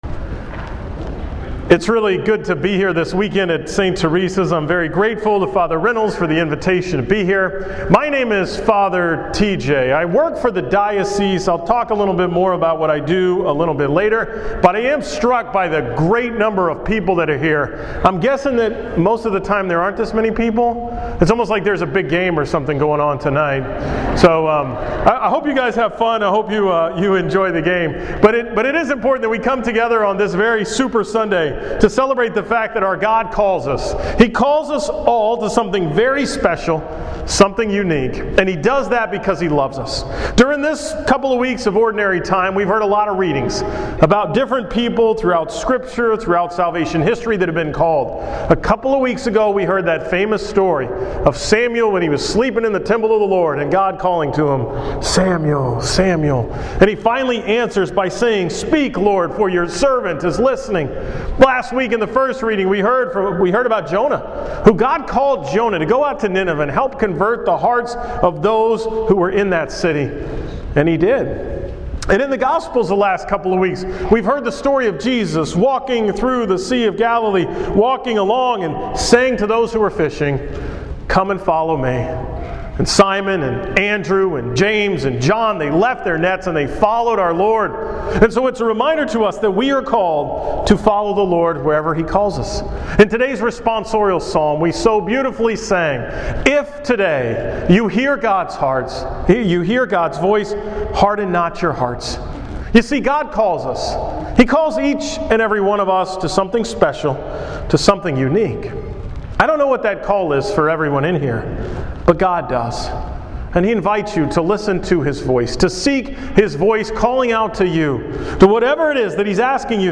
From the 12 pm Mass at St. Theresa in Sugarland on Sunday, February 1st